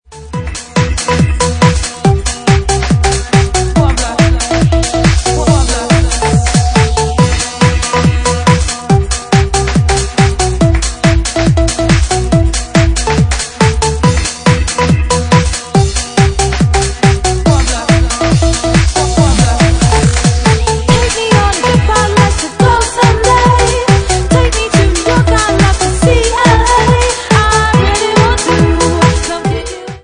Bassline House at 141 bpm